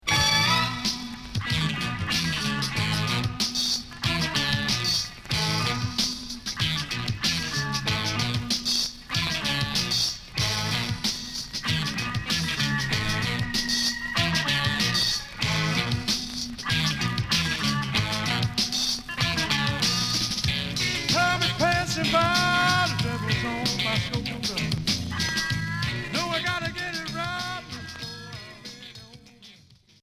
Groove pop Unique 45t retour à l'accueil